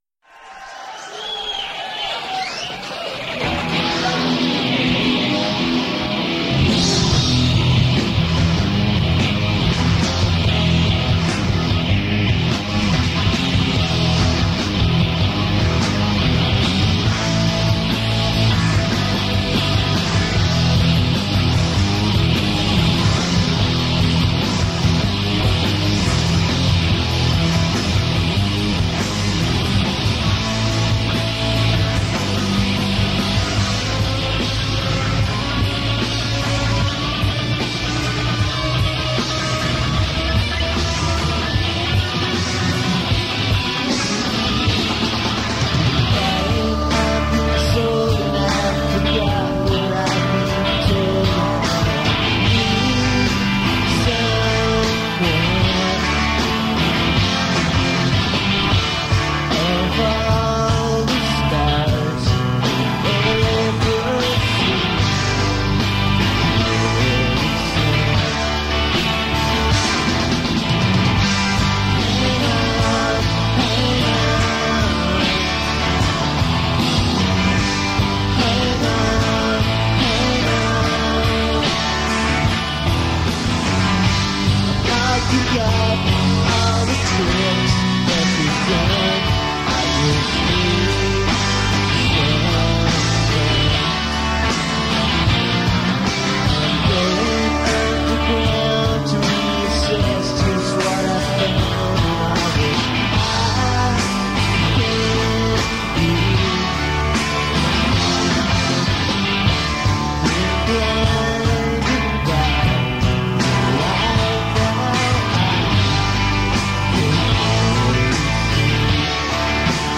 Scottish Alt/Indie/Jangle/Power group in session in Paris